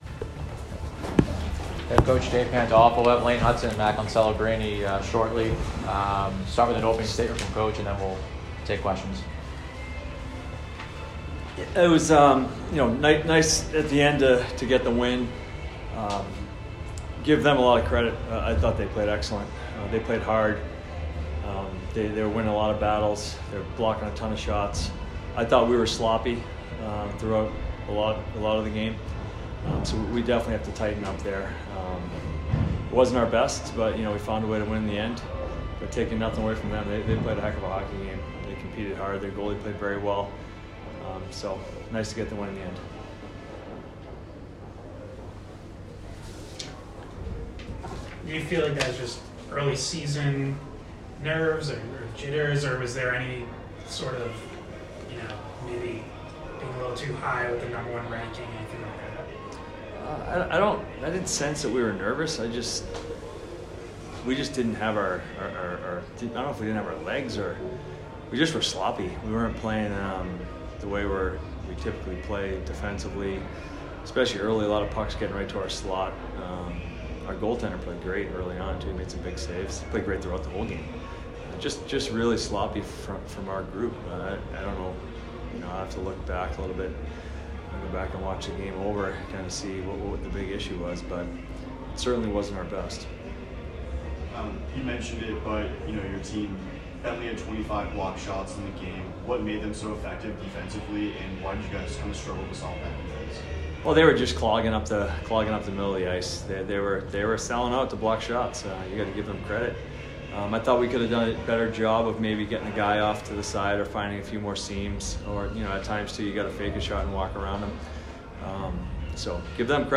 Men's Ice Hockey / Bentley Postgame Interview